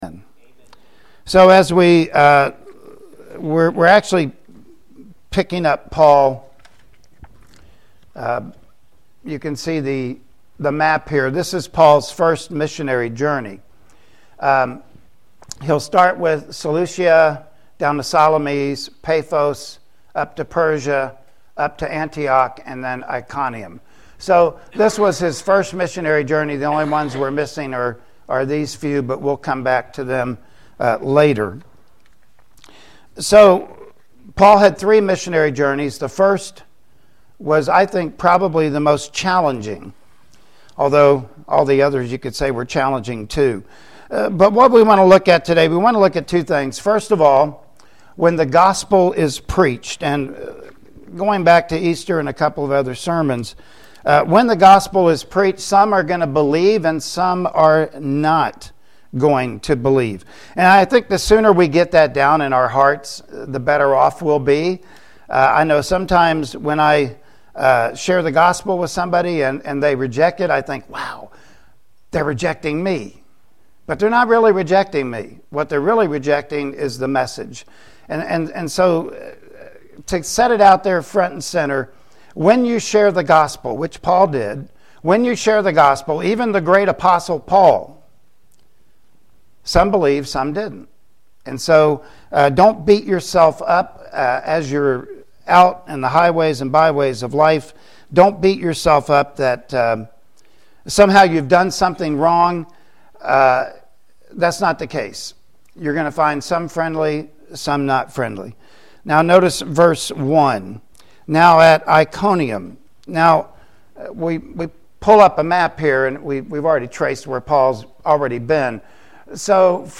Acts 14:1-7 Service Type: Sunday Morning Worship Service Topics: Witnessing